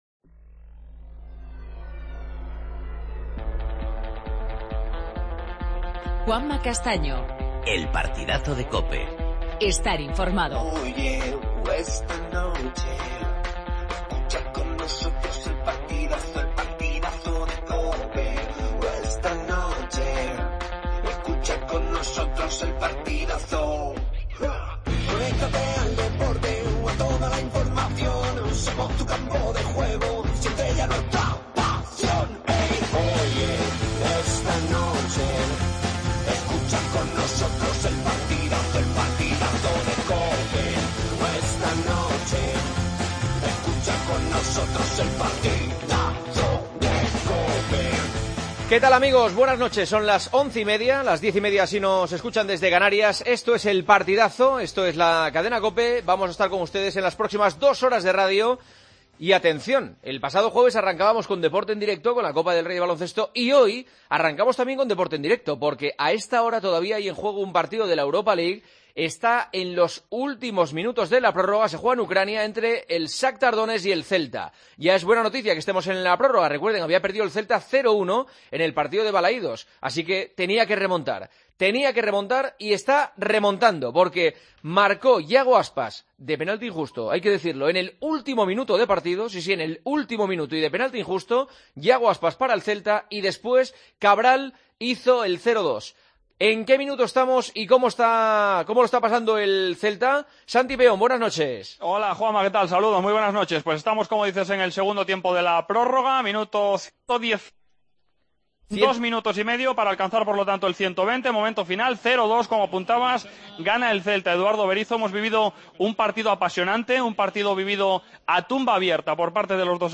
Escuchamos los últimos minutos del Shakhtar-Celta, partido de vuelta 1/16 de final Europa League.